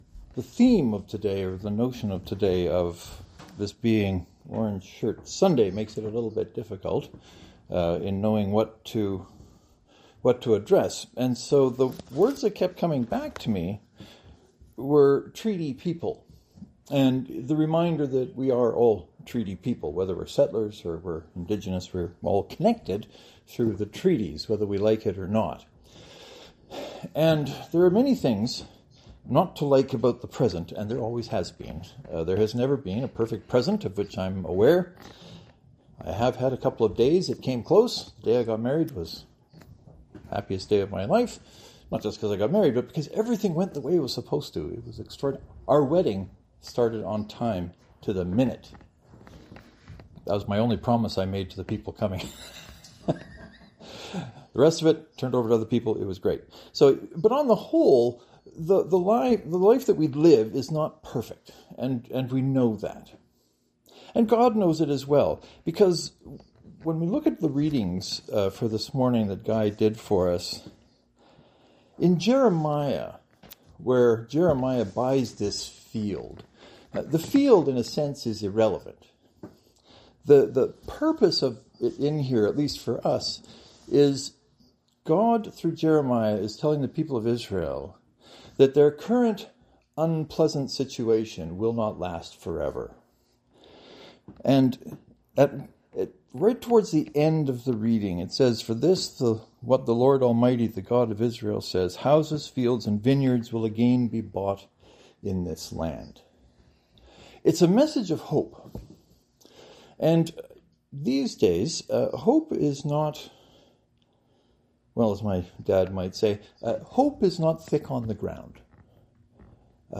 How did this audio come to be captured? Today was Orange Shirt Sunday.